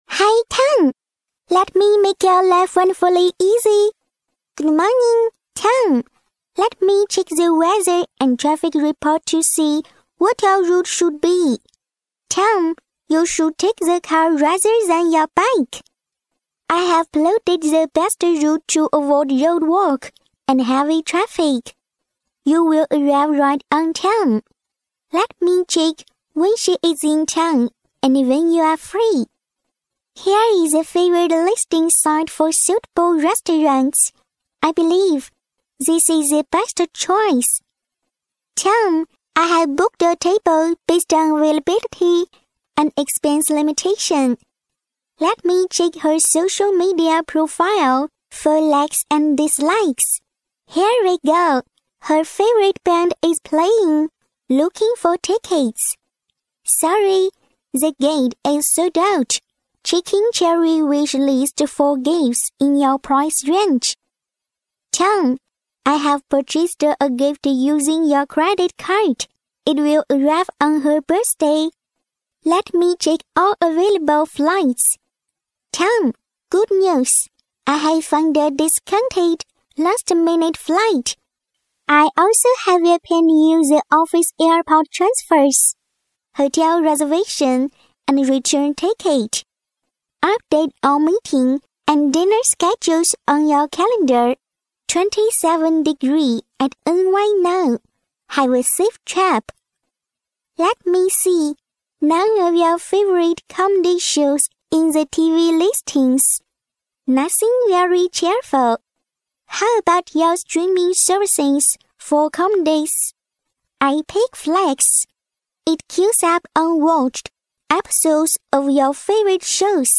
美式英语青年亲切甜美 、女宣传片 、课件PPT 、工程介绍 、40元/分钟女S17 国人英语 女声 宝马5系-英文-成熟磁性 亲切甜美